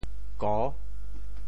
调: 弟
国际音标 [ko]